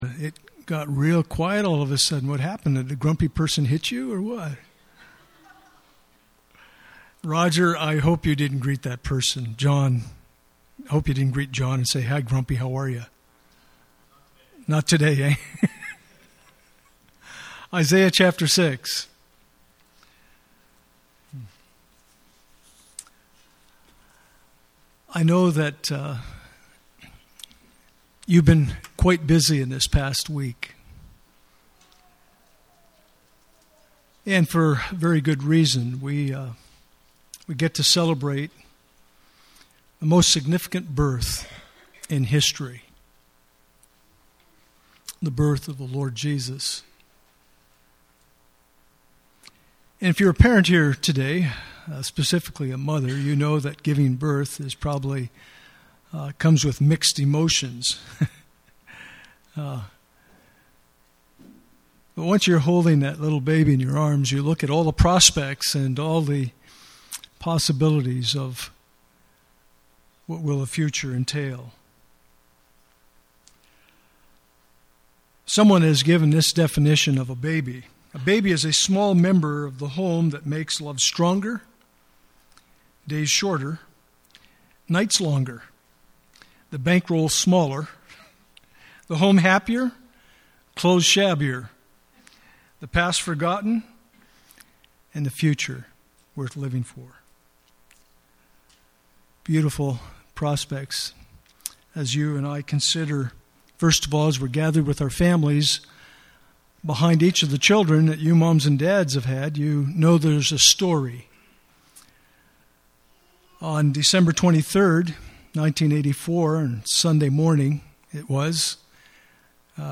Passage: Isaiah 6:1-8 Service Type: Sunday Morning